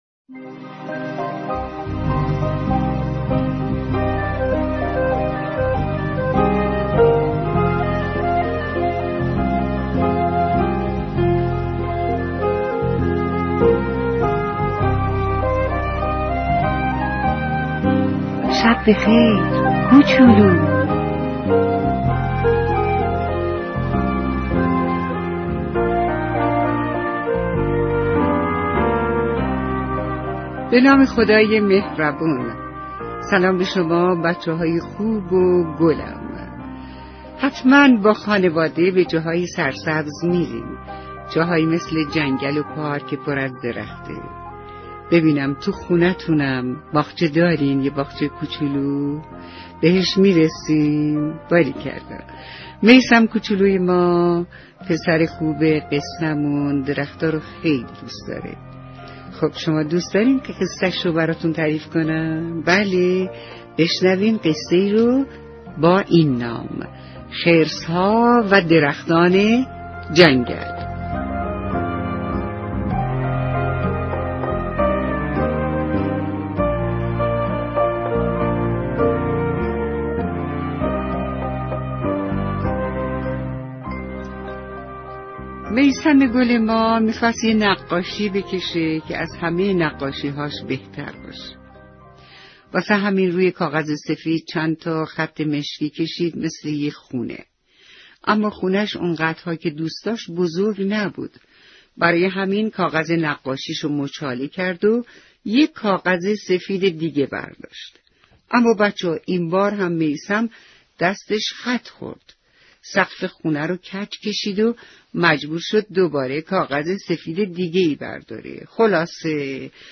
93 قصه صوتی شاتوتی (بهار)